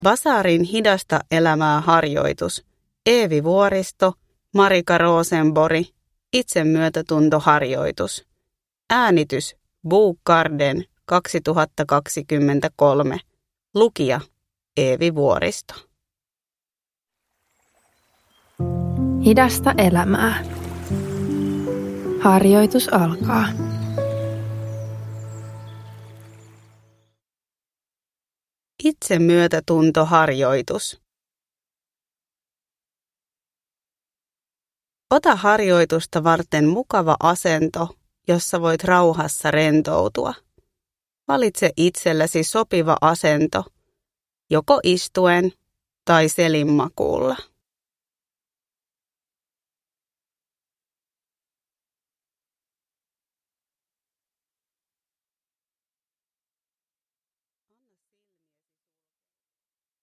Itsemyötätuntoharjoitus – Ljudbok
Rauhoittava harjoitus tunnetyöskentelyn avuksi